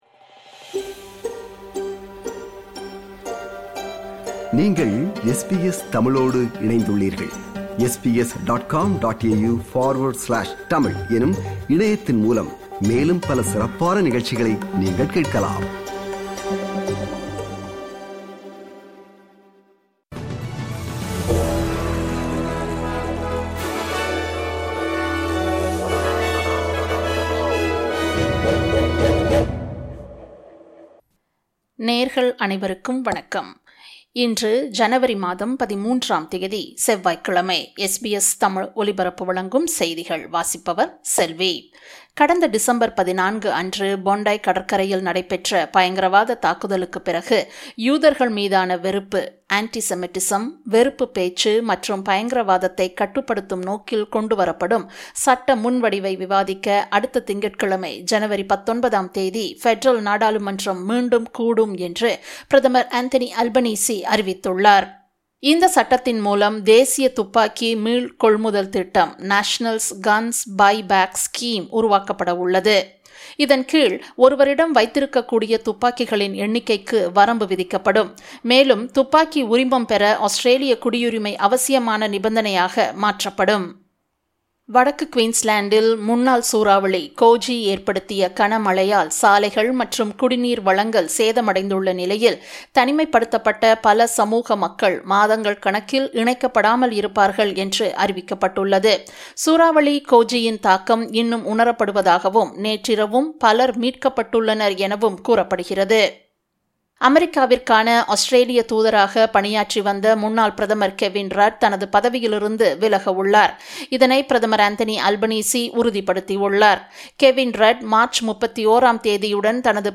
SBS தமிழ் ஒலிபரப்பின் இன்றைய (செவ்வாய்க்கிழமை 13/01/2026) செய்திகள்.